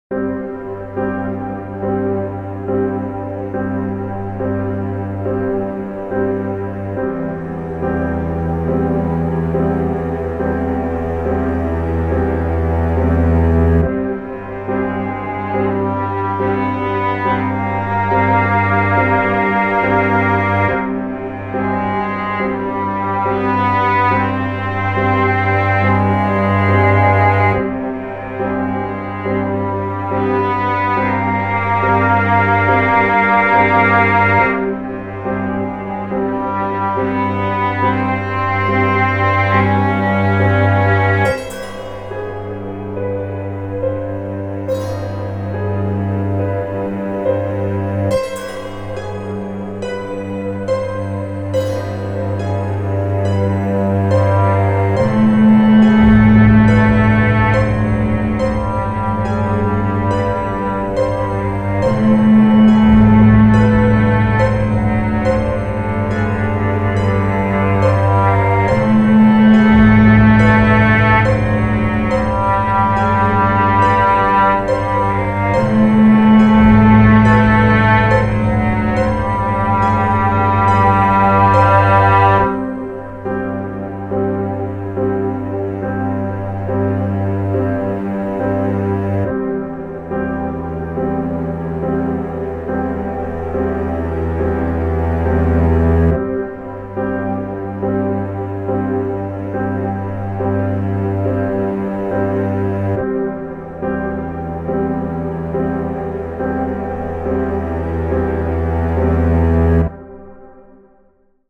フリーBGM ダンジョン
ダーク 孤独 恐怖